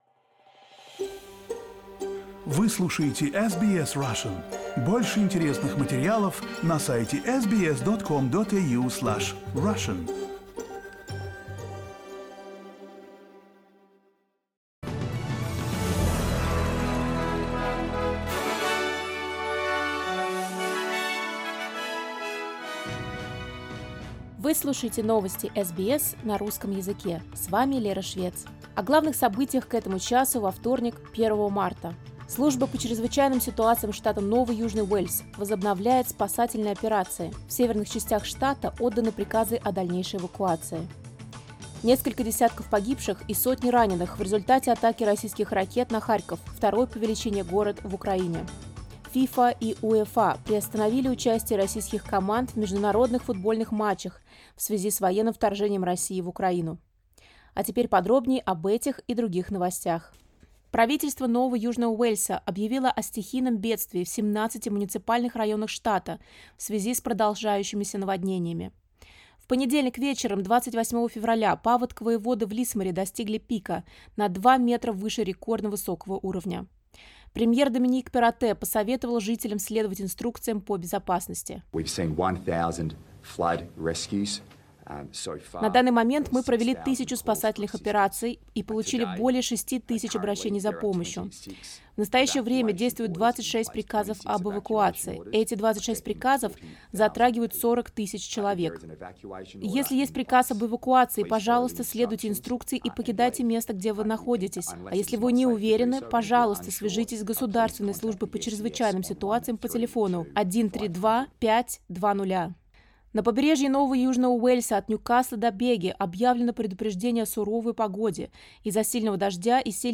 SBS news in Russian — 01.03